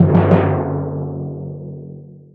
flag_lost.wav